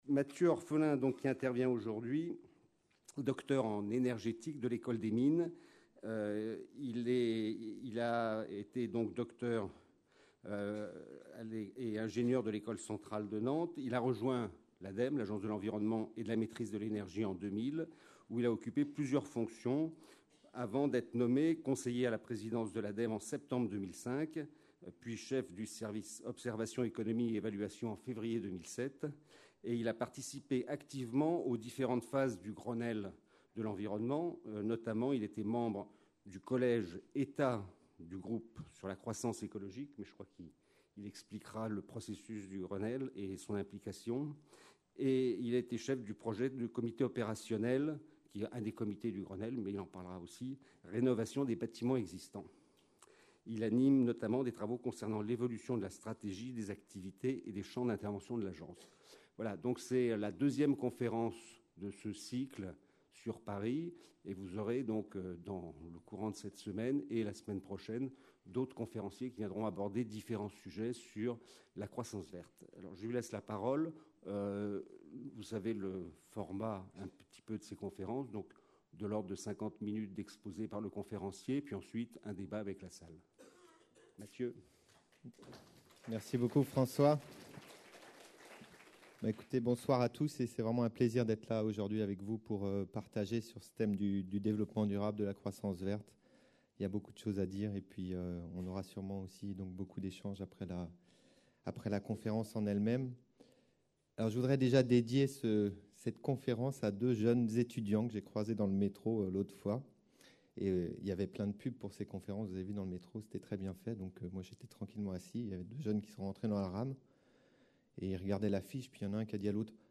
Agir pour une croissance verte Par Matthieu Orphelin, (docteur en énergétique de l'Ecole des mines, directeur énergie, air, bruit de l'ADEME) Un programme du cycle de conférences : Développement durable, la croissance verte : comment ?